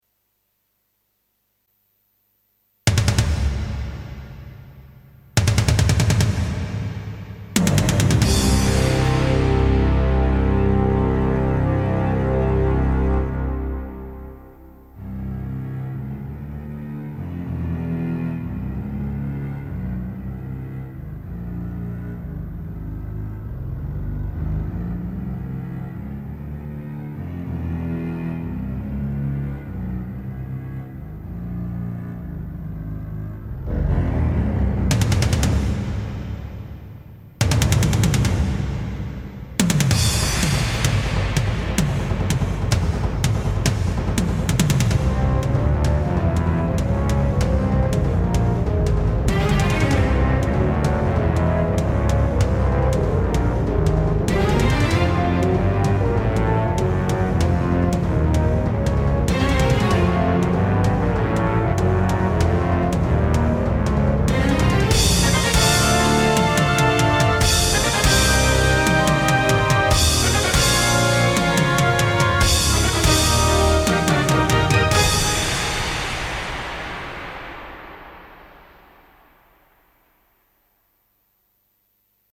Played by Solo Orchestra